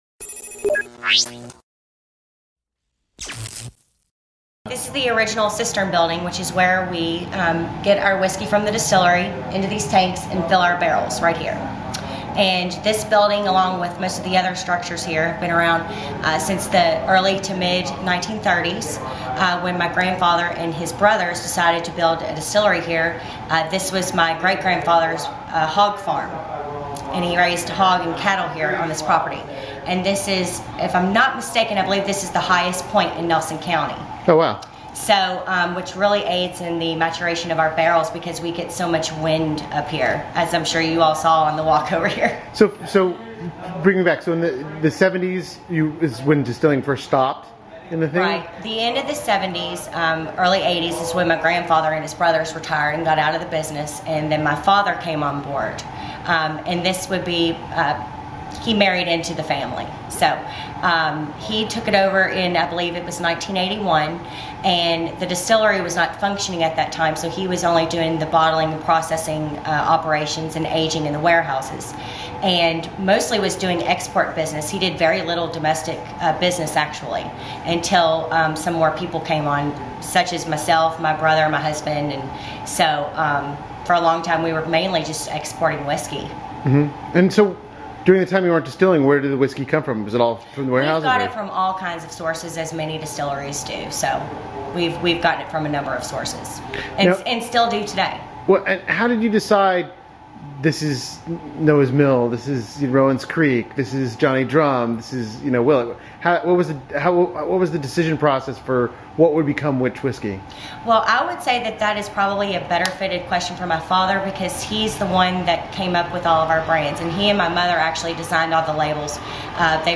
Women in Whiskey: Interview